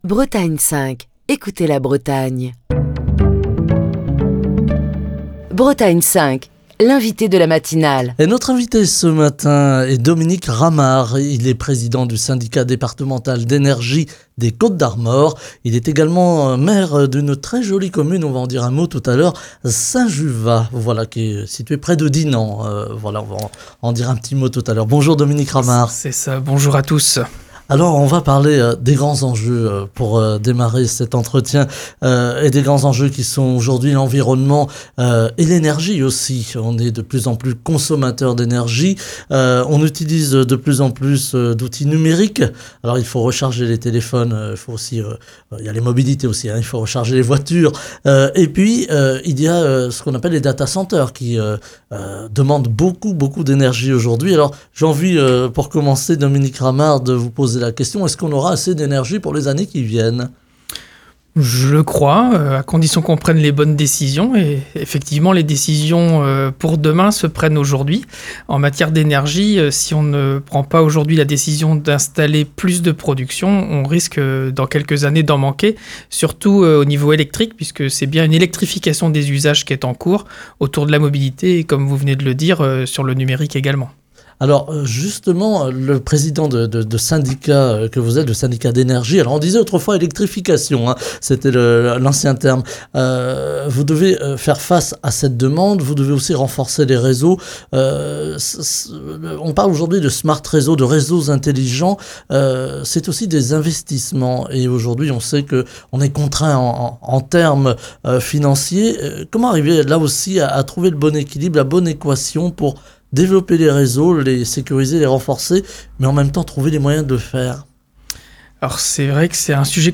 Ce matin, Dominique Ramard, président du Syndicat Départemental d’Énergie des Côtes-d'Armor et maire de Saint-Juvat, était l’invité de la matinale de Bretagne 5. Au micro de Bretagne 5 Matin, Dominique Ramard a abordé les grands défis énergétiques à venir. Il a notamment insisté sur l’impérieuse nécessité d’anticiper les besoins croissants en électricité, dans un contexte de transition énergétique et de consommation toujours plus soutenue.